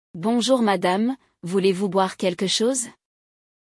Le dialogue (O diálogo)